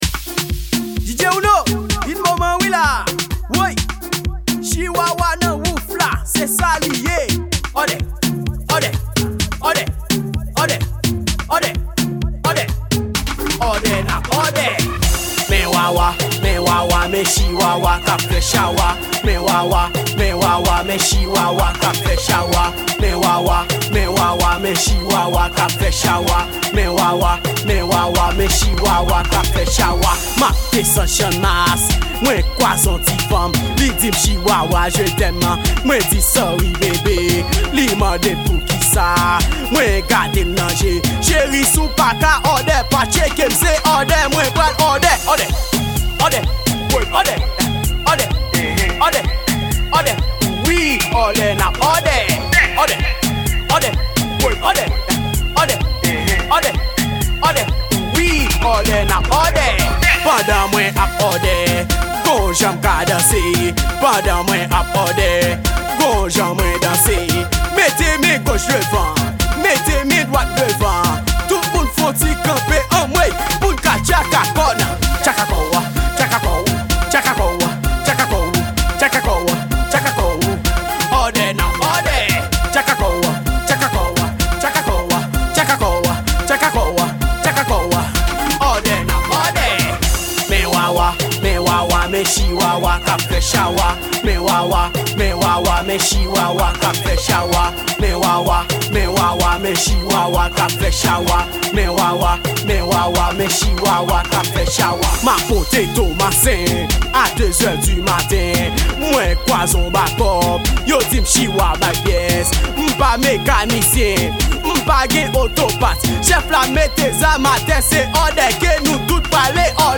Genre: Mixtape.